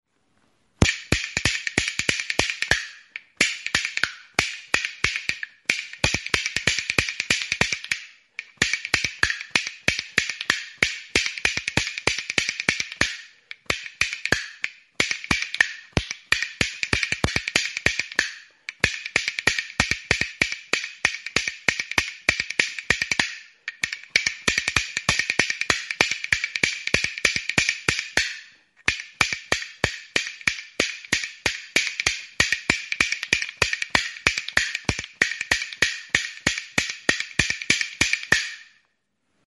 Grabado con este instrumento.
TXARAMELAK ; ARXALUAK ; PALUEK ; TARRAÑUELAS
Idiófonos -> Golpeados -> Indirectamente
Zurezko bi tablatxo.